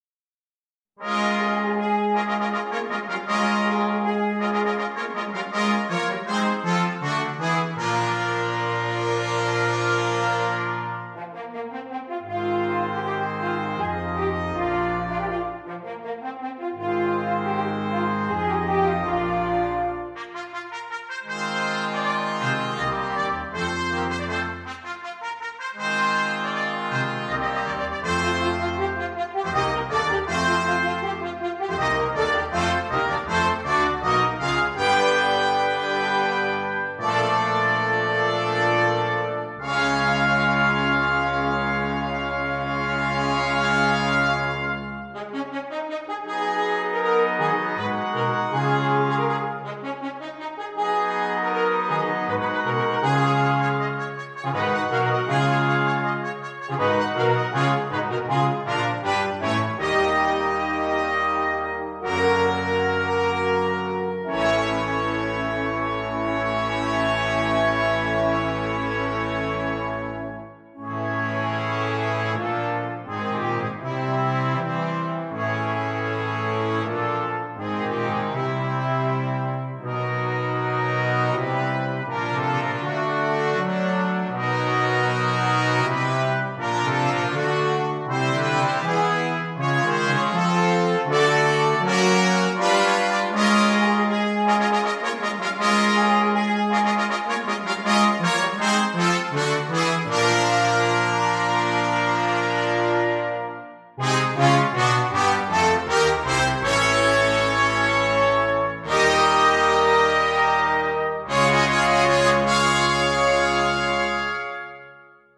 Brass Choir (3.4.3.0.1)